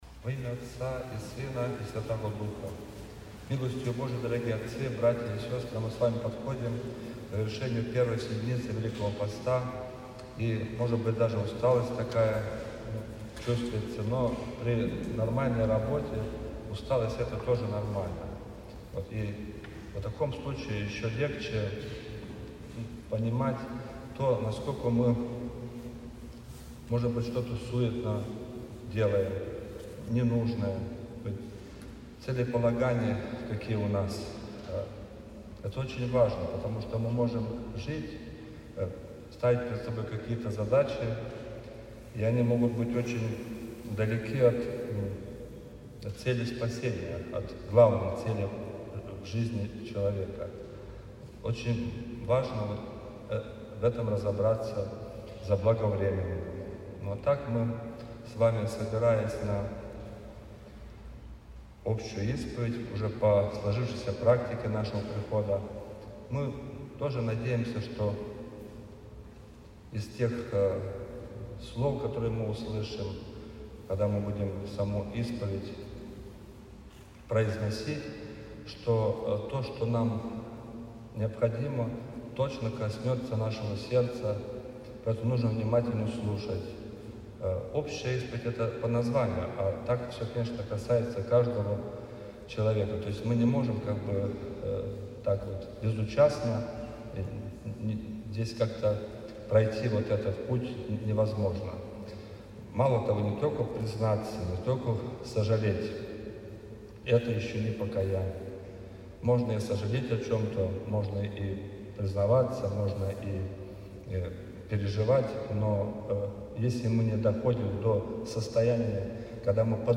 После богослужения, по сложившейся приходской традиции, был совершена общая исповедь.
Общая-исповедь.mp3